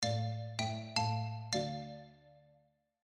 Plays short end of the track